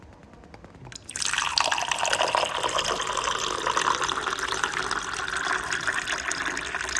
Liquid-Dispensing like 0